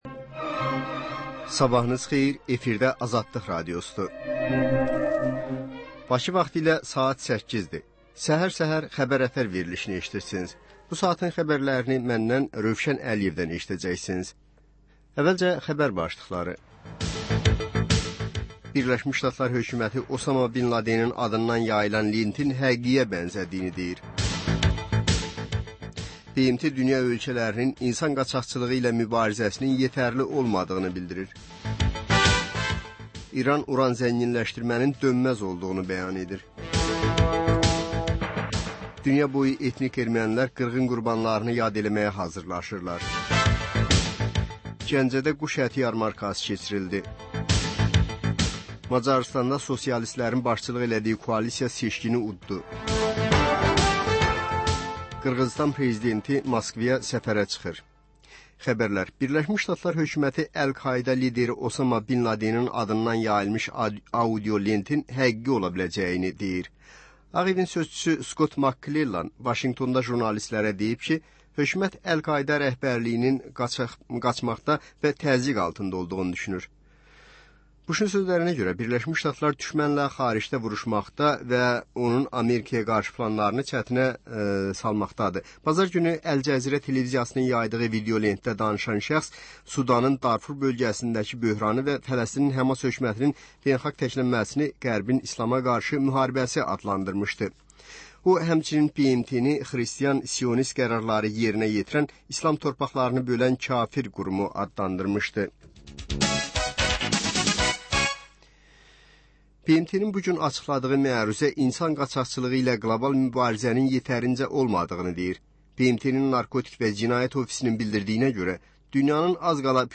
Səhər-səhər, Xəbər-ətər: xəbərlər, reportajlar, müsahibələr İZ: Mədəniyyət proqramı. Və: Tanınmışlar: Ölkənin tanınmış simalarıyla söhbət.